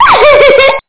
LAUGH6.mp3